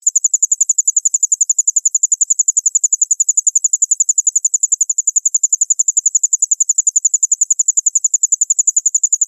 Cricket Chirping Bouton sonore
Animal Sounds Soundboard1,791 views